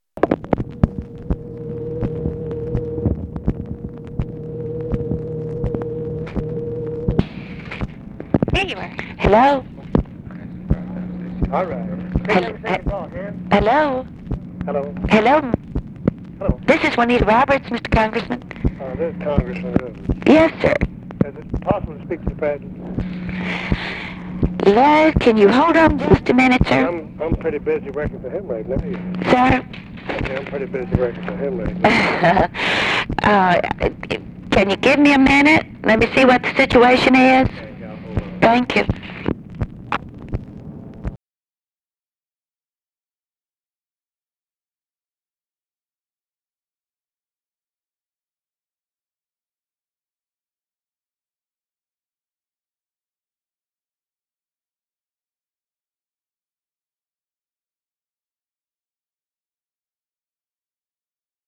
Conversation with MENDEL RIVERS, May 3, 1965
Secret White House Tapes